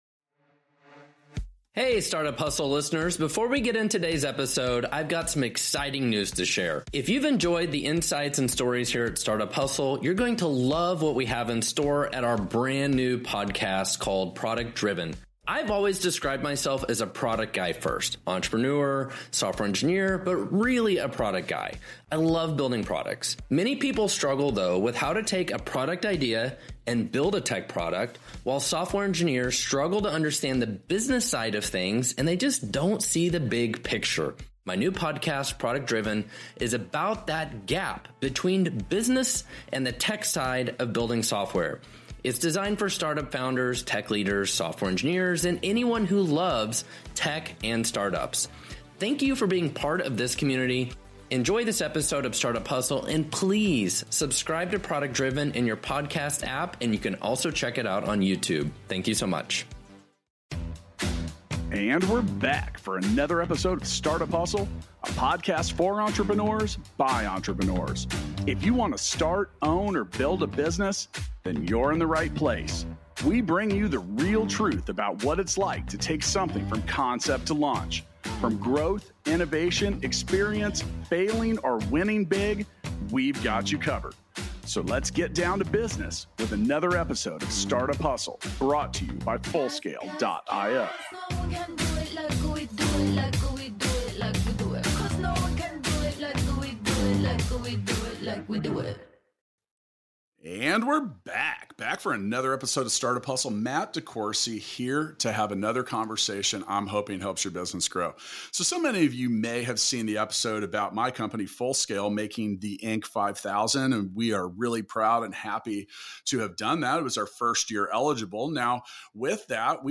What a fun conversation